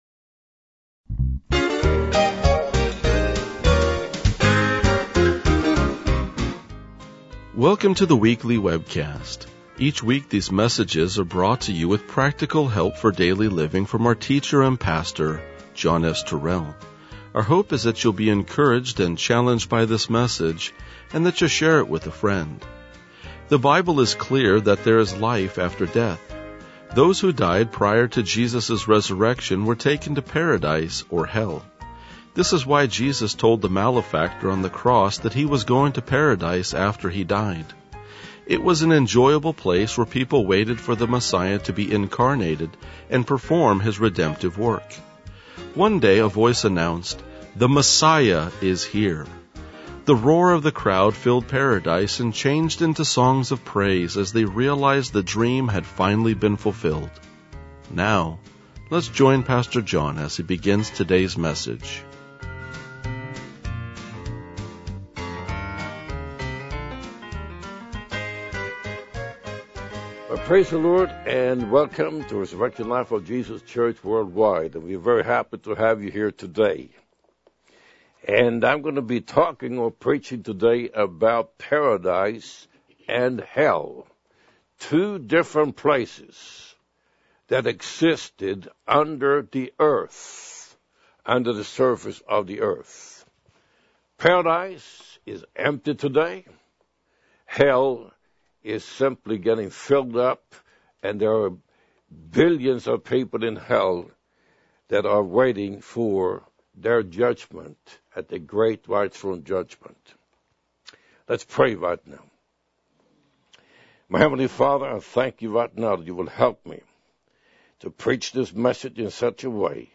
RLJ-1989-Sermon.mp3